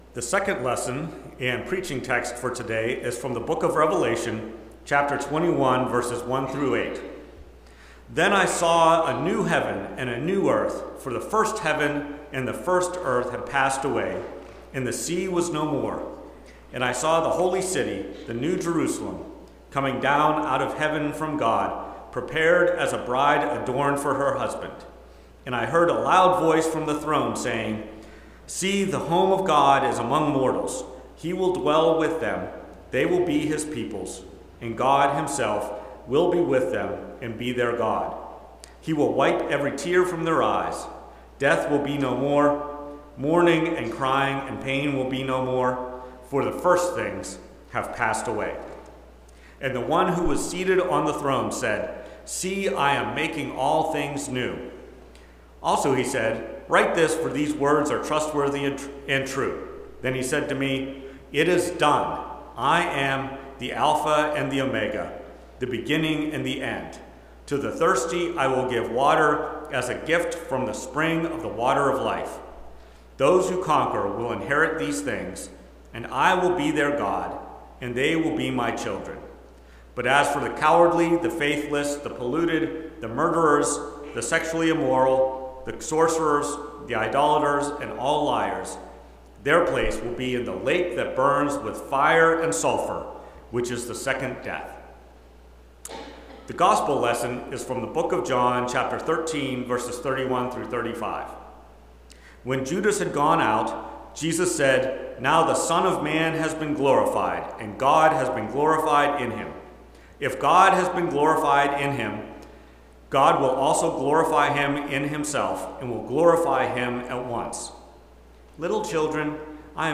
Preached May 18, 2025, at First Presbyterian Church of Rolla. Based on Revelation 21:1-8.